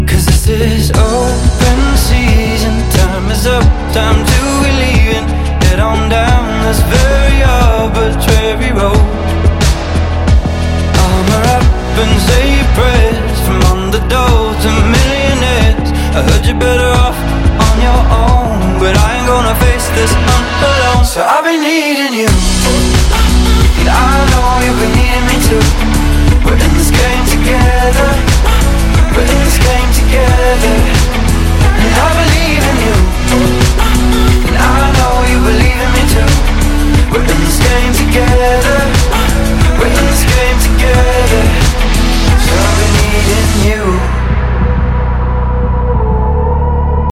• Качество: 320, Stereo
поп
мужской вокал
dance
Electropop